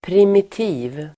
Uttal: [pr'im:iti:v (el. -'i:v)]